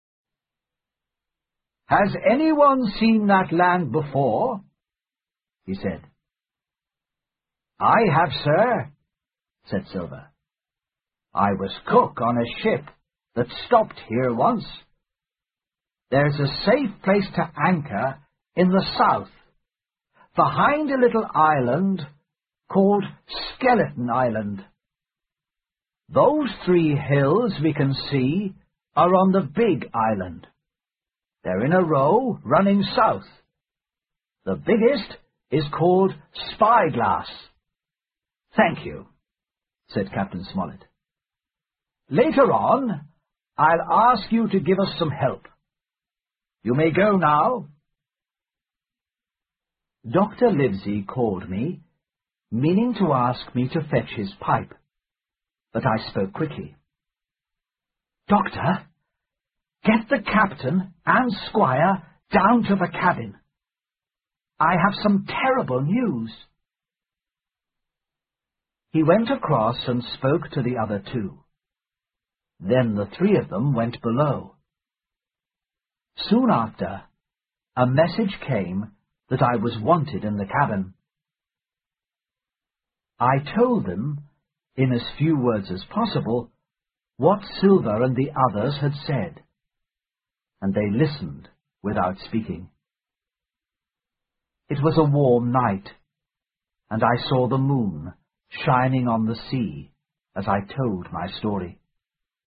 在线英语听力室《金银岛》第七章 苹果桶(7)的听力文件下载,《金银岛》中英双语有声读物附MP3下载